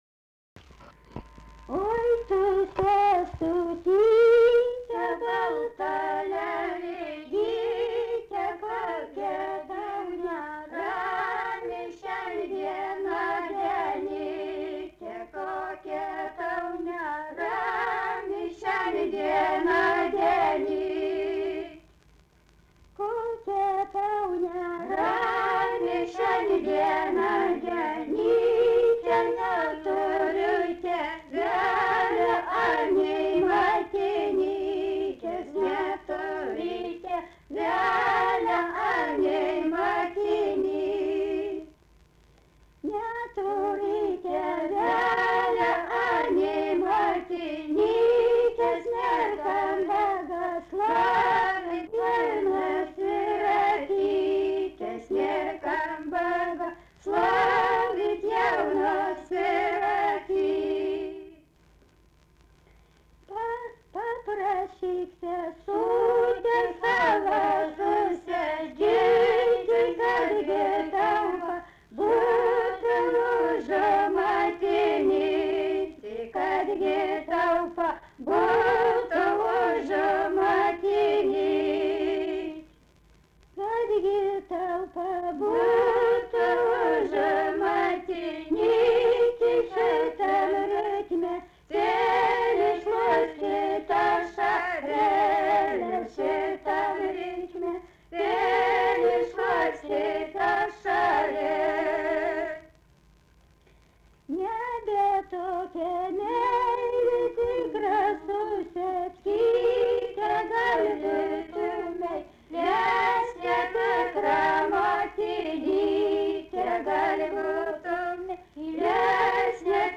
Dalykas, tema daina
Atlikimo pubūdis vokalinis